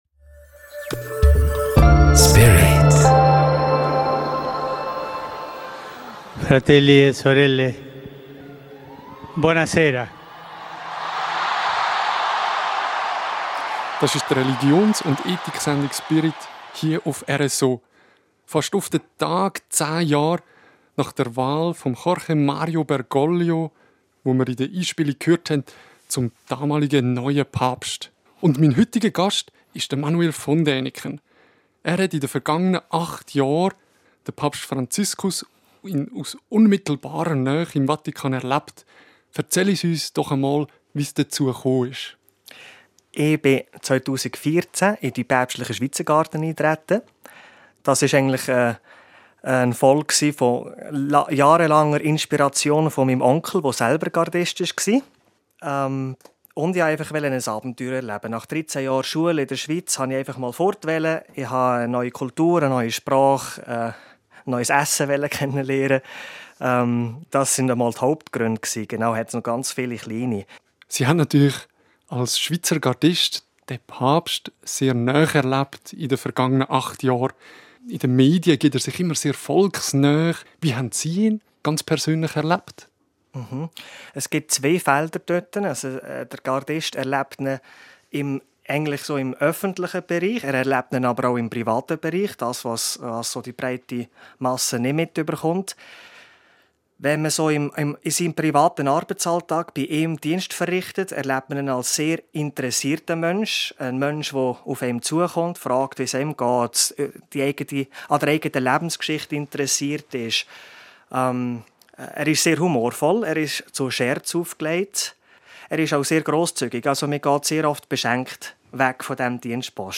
Im Interview erzählt er von seinen Erfahrungen mit dem amtierenden Papst, er gibt Einblicke in das Leben im Vatikanstaat und er erzählt, woran sich der Papst bei seiner Abschiedsaudienz am meisten gefreut hat.